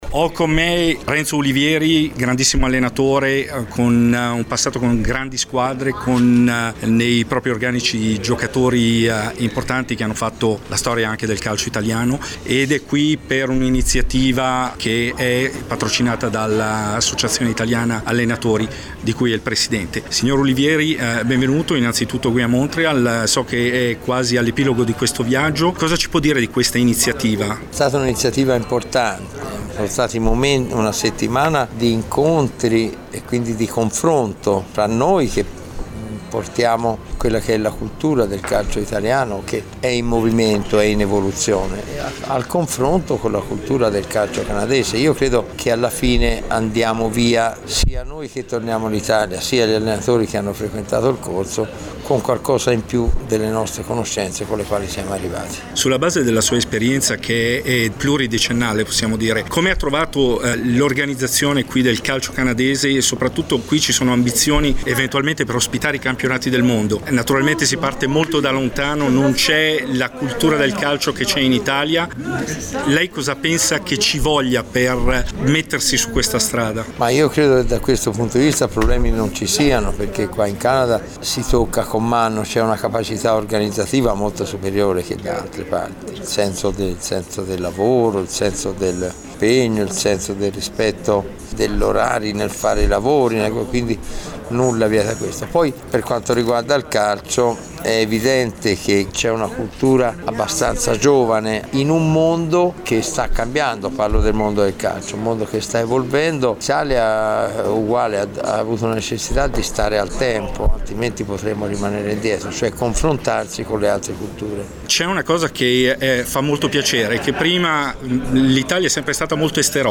Nell’ambito di un incontro organizzato dal GISS (Genova International School of Soccer) e con il patrocinio dell’AIAC, ho avuto il piacere di intervistare Renzo Ulivieri.